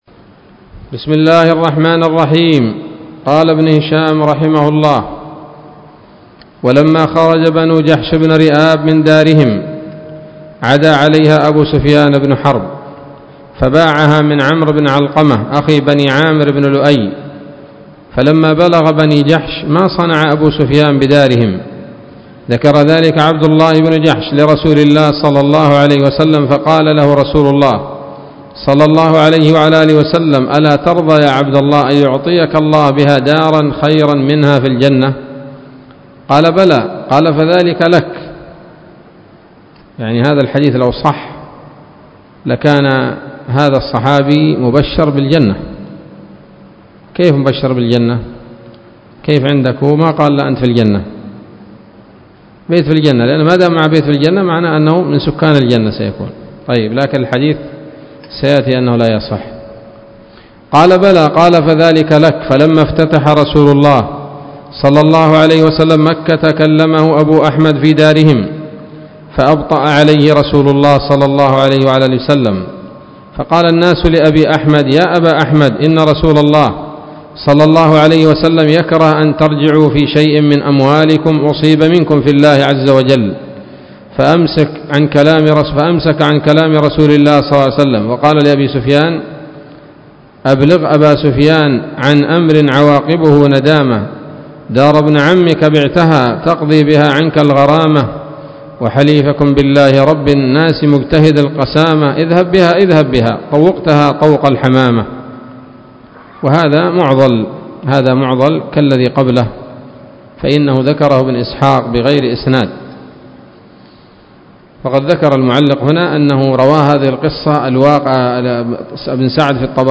الدرس السابع والسبعون من التعليق على كتاب السيرة النبوية لابن هشام